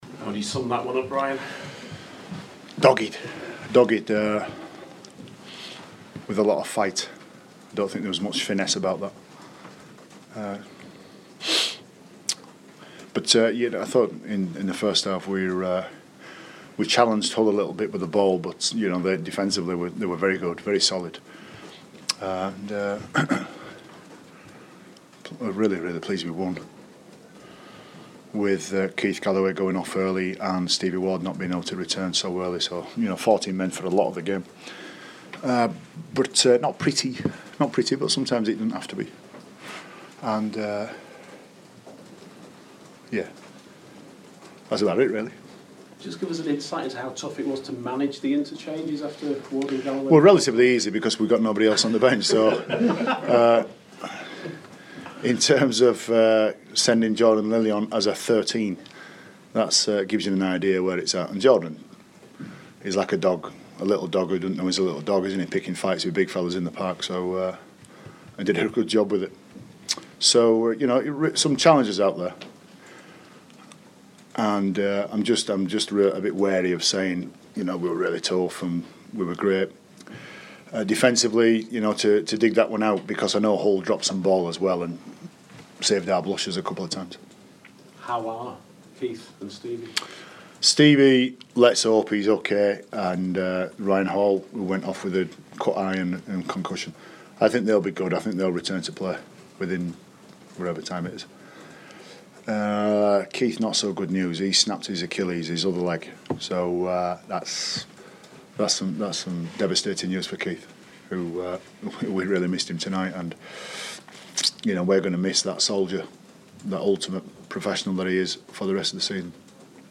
Leeds Rhinos head coach spoke to the press after a tight win over Hull FC 10-7 on Friday night at Headingley. The Rhinos stay 2nd in the Super League.